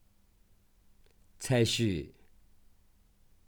33踩水